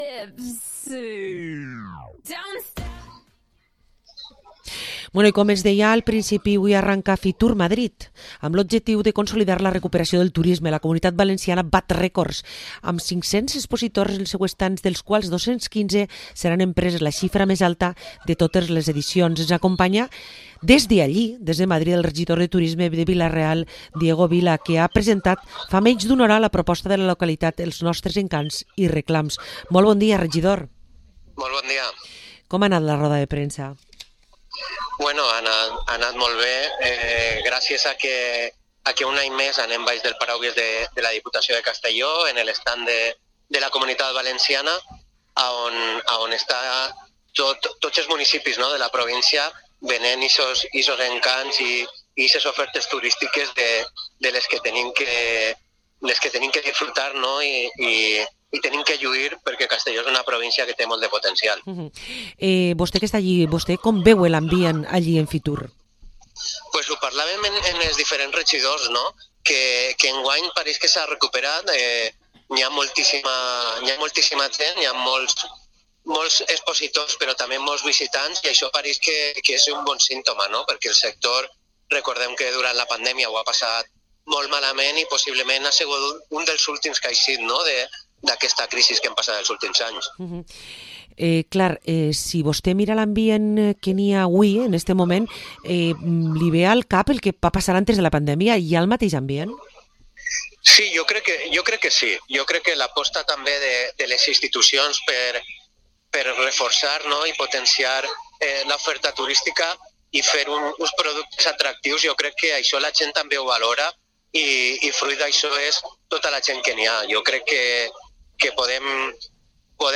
🌳 El regidor de Turisme de Vila-real, Diego Vila, ha presentat aquest migdia a Fitur els encants i reclams de la ciutat…. patrimoni, història i molta natura…i que ens conta en viu com ha viscut aquesta primera edició postpandèmia.